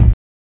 HAT THUD.wav